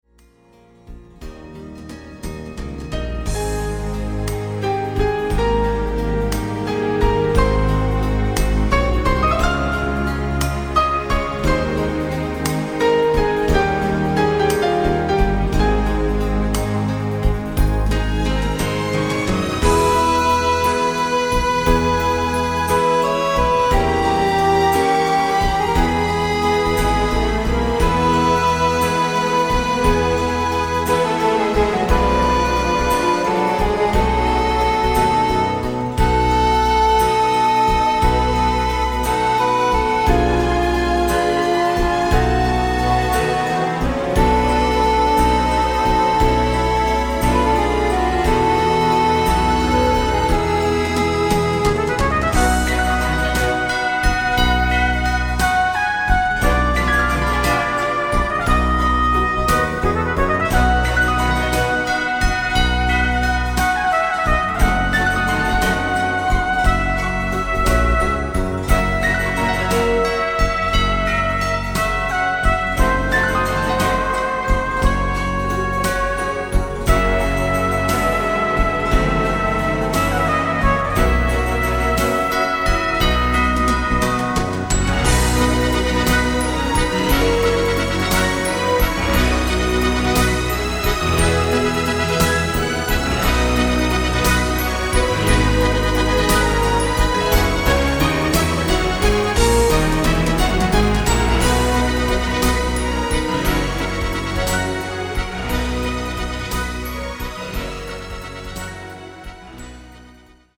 Slow Rock